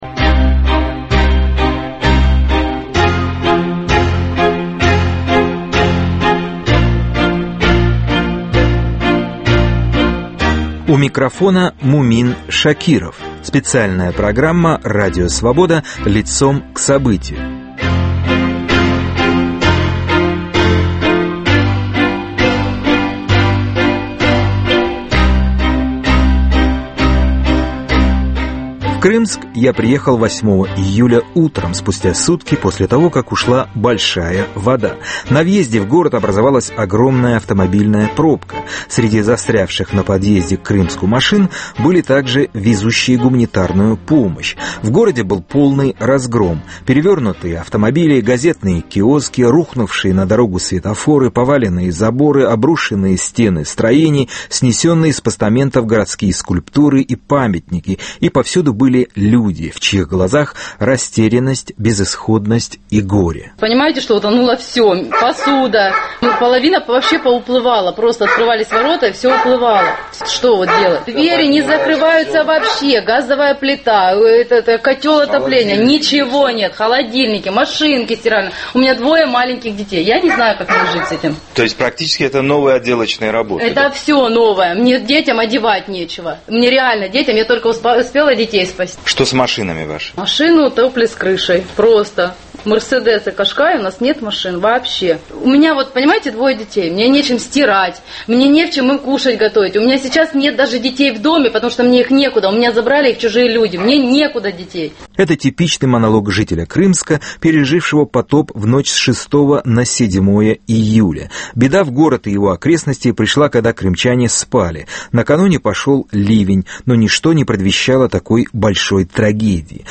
Специальная программа Радио Свобода, посвященная наводнению в Крымске. Рассказы очевидцев, аргументы властей, помощь волонтеров и угроза эпидемии, - трагедия глазами журналиста.